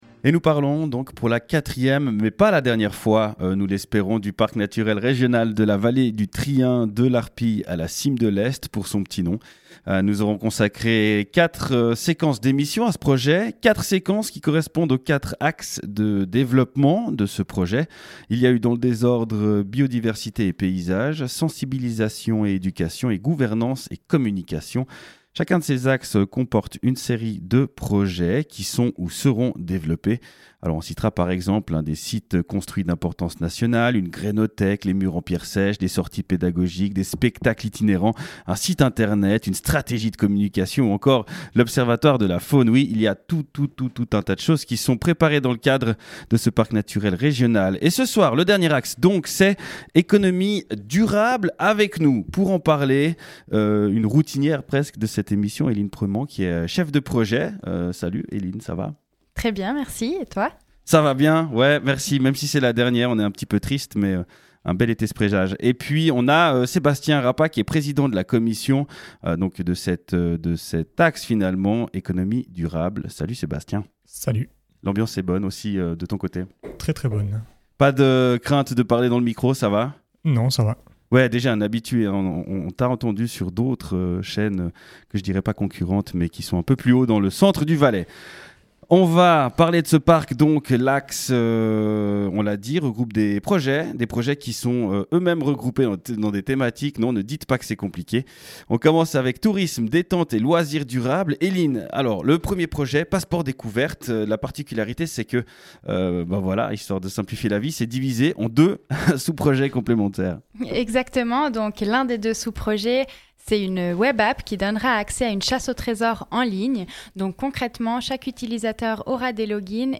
Tourisme, économie de proximité, gastronomie, mobilité et ressources naturelles seront au menu de cet entretien.